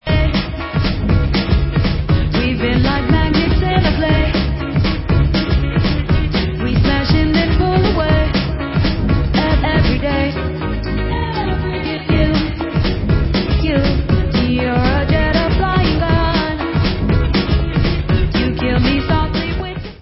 sledovat novinky v oddělení Alternative Rock